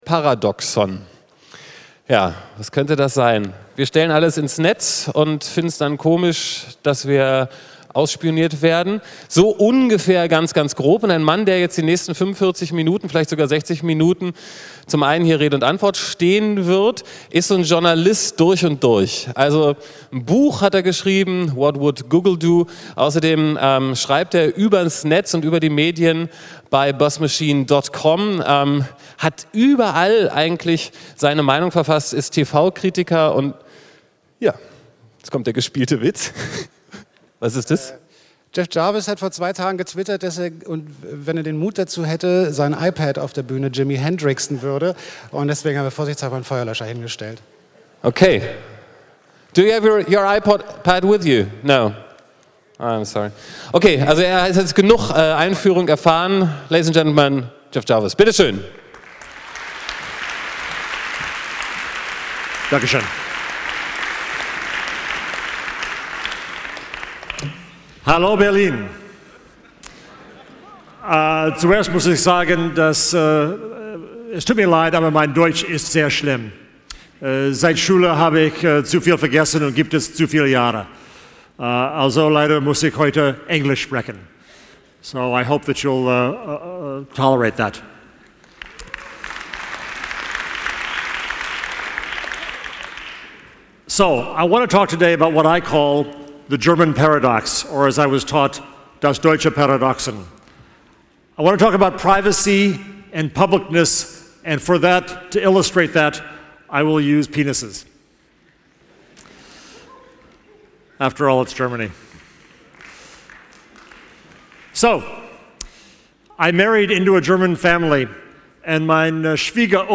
Hier ist erst einmal der Audiomitschnitt.